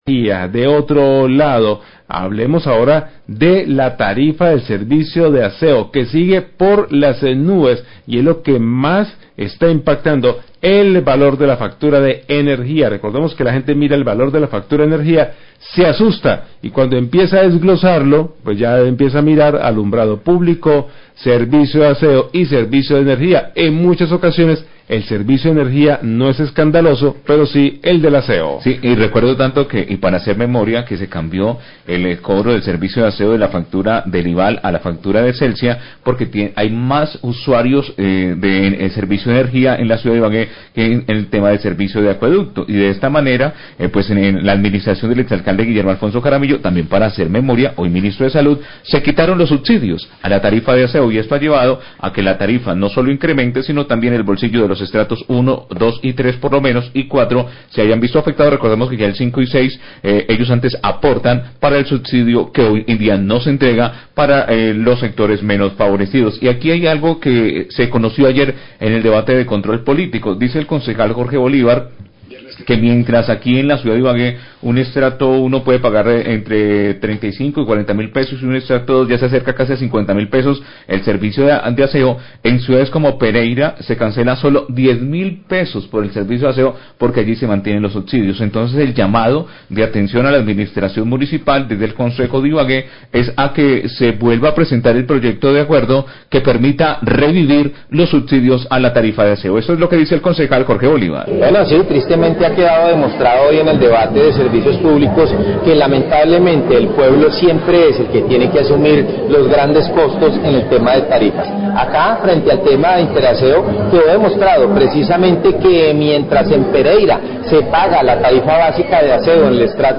Radio
El concejal de Ibagué, Jorge Bolívar, habla de los debates de control político a las empresas Interaseo, Celsia y Alcanos por deficiencias en la prestación del servicio. Denuncia las altas tarifas que cobra Interaseo por el servicio de recolección de basuras que supera a cualquier tarifa en el resto del país e incrementa el valor de la factura de energía en donde se cobra este servicio.